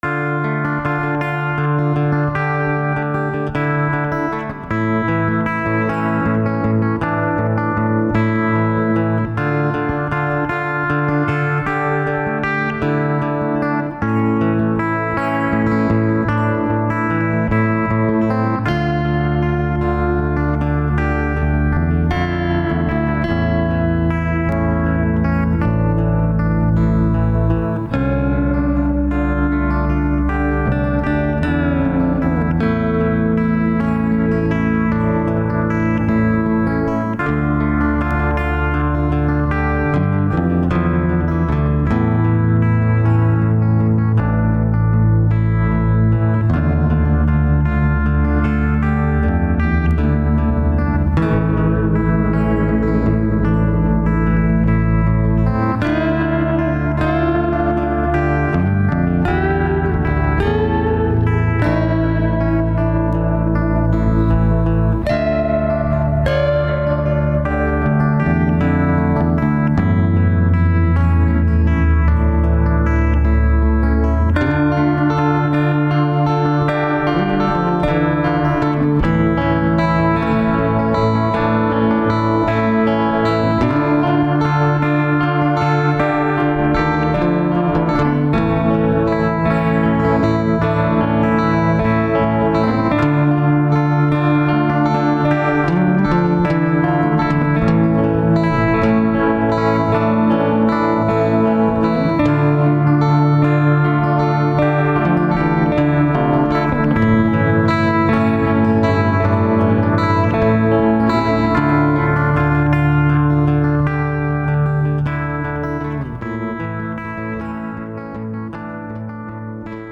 and yes I know my bass is horribly out of tune and I hit a wrong note
Really really good. I love the atmosphere of it so much.
It's supposed to be a sad song but I couldn't write sad lyrics.
It's very repetitive but other than that I think it's great as a standalone piece.